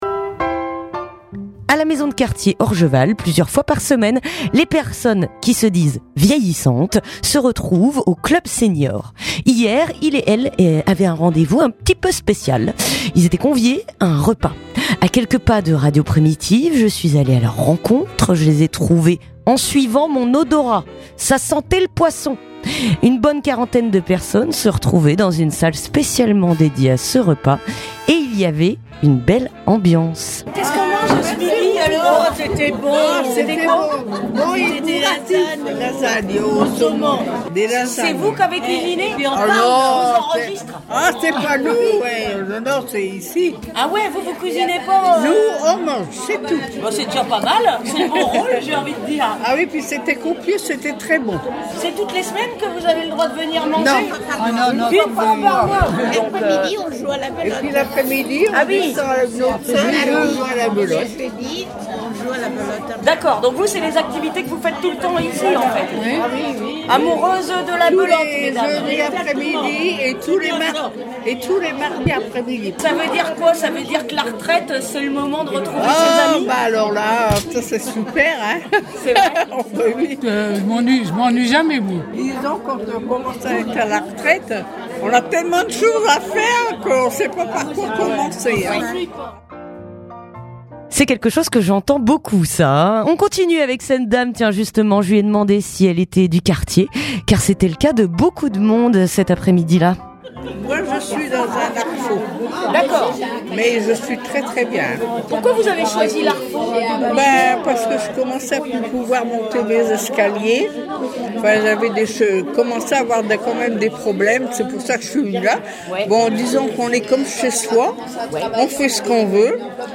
Reportage à Orgeval (18:39)